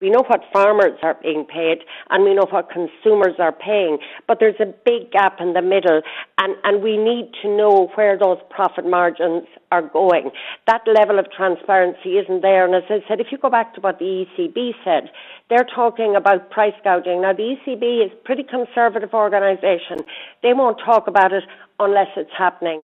Independent TD Marian Harkin thinks processors should be forced to reveal how much they’re paid by retailers: